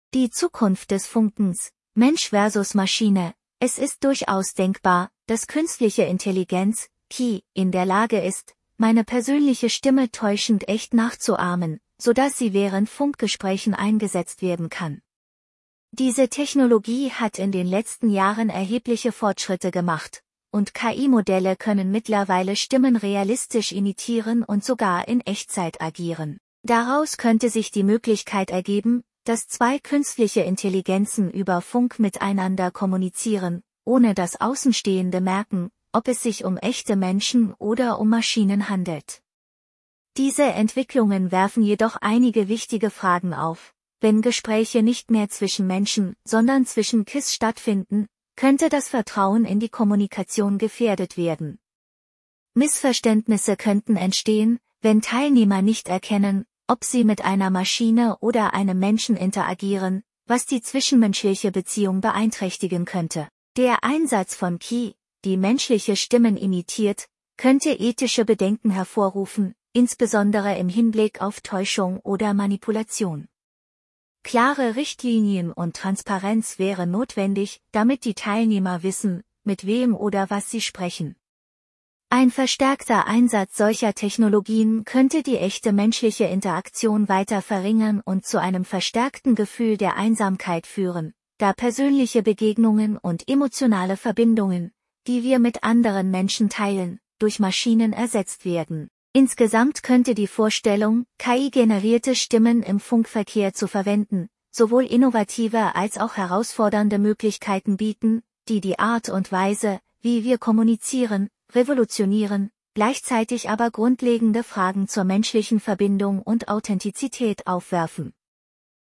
Diesen Text (Stand 2.10.24) in drei Abschnitten vorlesen lassen: Erstellt mit ebenfalls künstlichen Stimmen von TTSMAKER